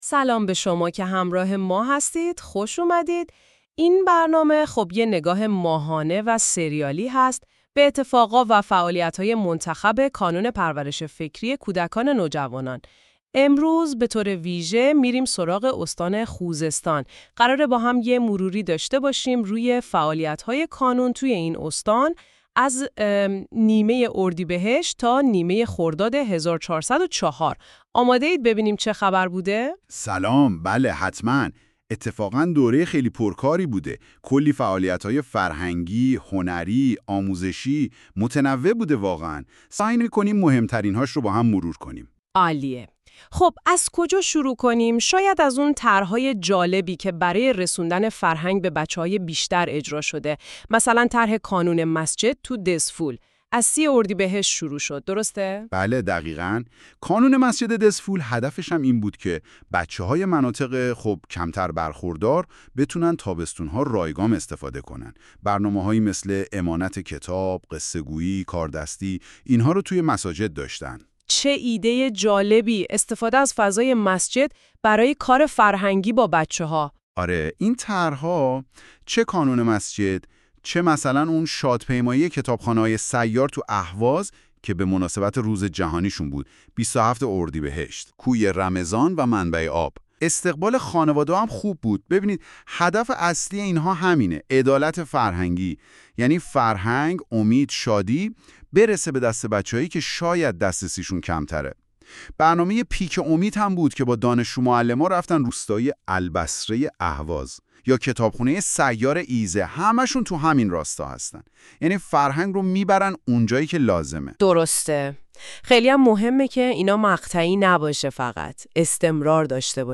دومین شماره پادکست خبری «نواماه»